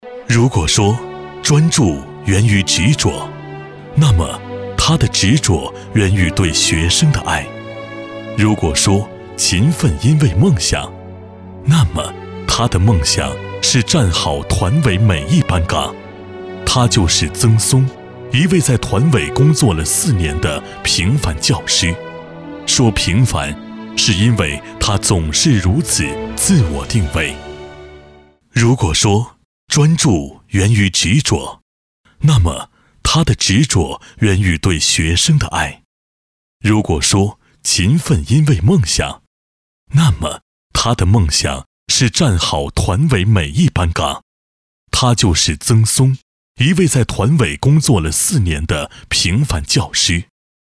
深情缓慢 人物专题
青年质感浑厚男中音，大气，稳重，磁性，厚实，擅长宣传，专题，记录等常见不同题材。